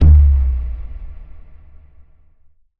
cinematic_deep_boom_impact_01.wav